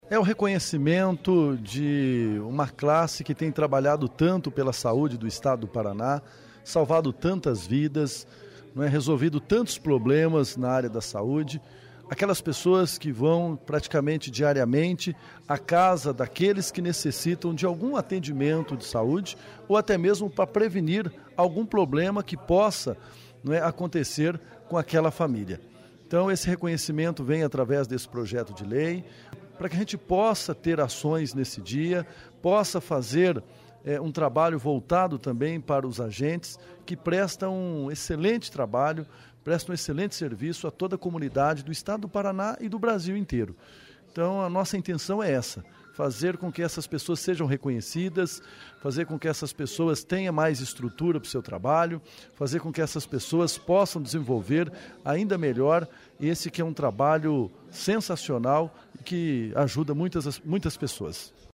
Ouça entrevista com o autor da proposta, o deputado Cobra Repórter. O projeto de lei nstitui o Dia Estadual do Agente Comunitário de Saúde e do Agente de Combate a Endemias, a ser promovido anualmente no dia 4 de outubro.